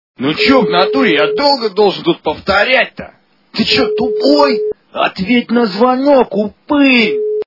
» Звуки » Люди фразы » Блотной голос - Ты че тупой ответь на звонок упир
При прослушивании Блотной голос - Ты че тупой ответь на звонок упир качество понижено и присутствуют гудки.